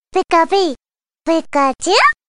Голос Покемона Пикачу